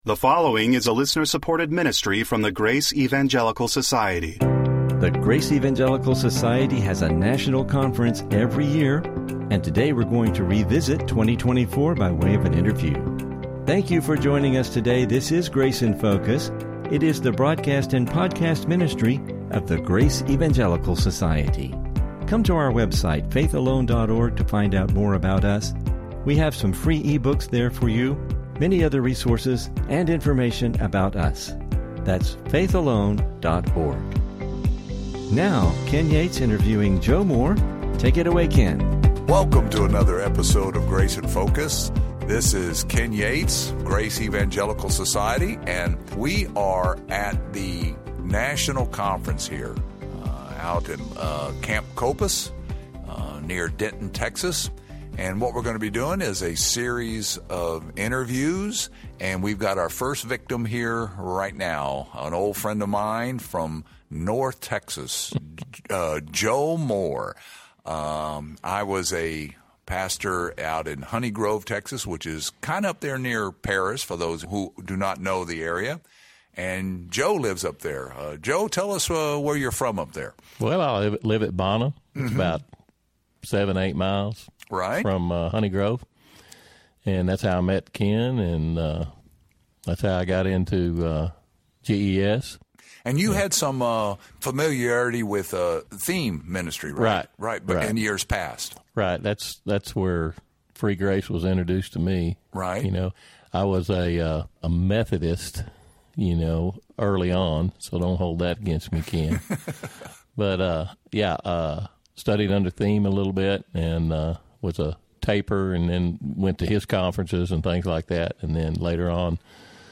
Interview National Conference 2024 Attendee